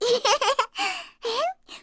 Luna-vox-get_05_kr.wav